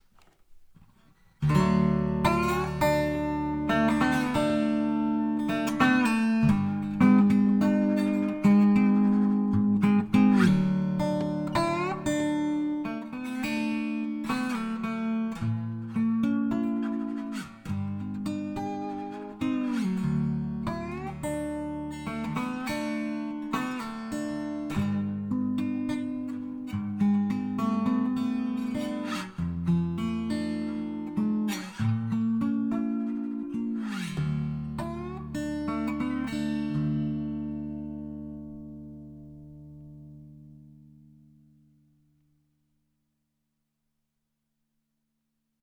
Here is a little demo of it's sound. I did not play lap steel for decades, and open D tuning is completely new to me, but maybe you get an idea of why I like it:
Musima Melodie lap steel.mp3
musima-melodie-lap-steel.mp3